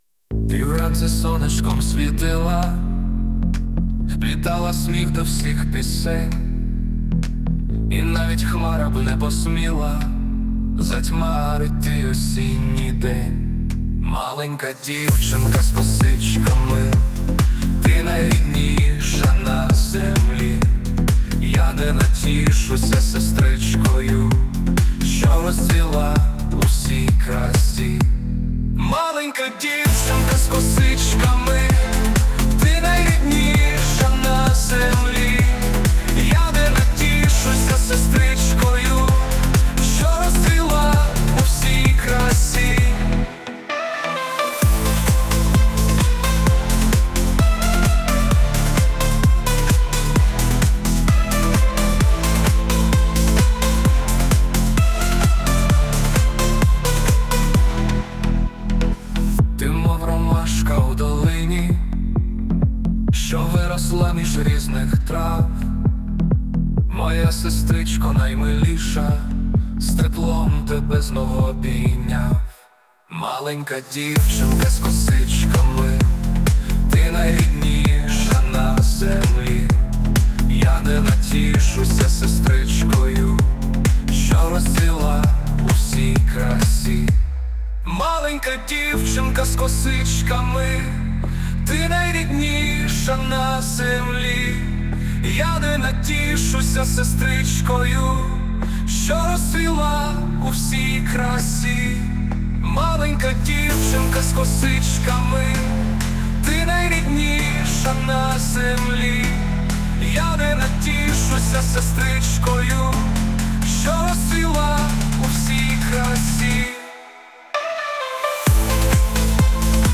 Сестричка _02_ (ukr) (remix).mp3
Музика та голос =SUNO
СТИЛЬОВІ ЖАНРИ: Ліричний
ВИД ТВОРУ: Пісня